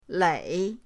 lei3.mp3